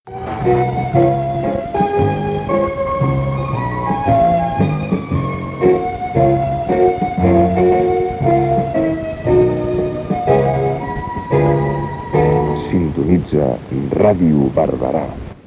a5465f4ca418cbeeab15293e180adbfcdf4a1896.mp3 Títol Ràdio Barberà Emissora Ràdio Barberà Titularitat Pública municipal Descripció Identificació de l 'emissora.